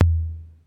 TOM XC.TOM0I.wav